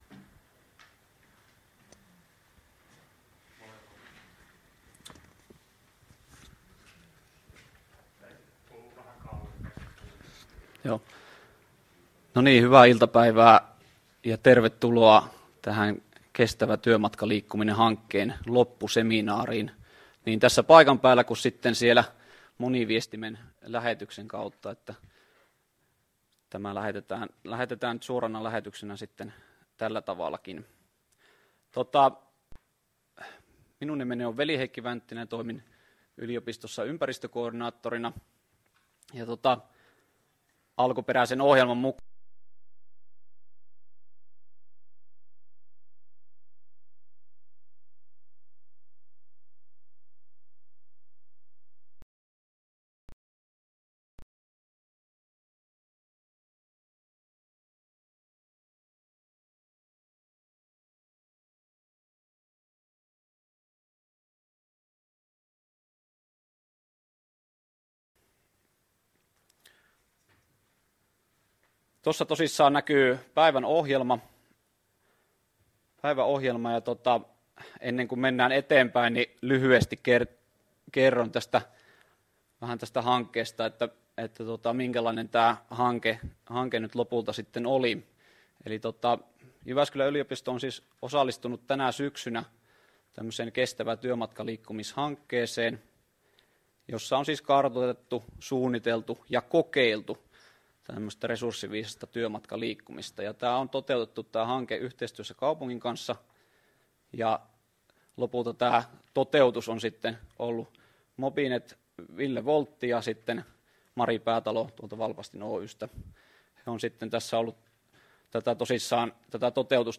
Kestävä työmatkaliikkuminen -hankkeen loppuseminaari